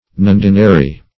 Nundinal \Nun"di*nal\, Nundinary \Nun"di*na*ry\, a. [L.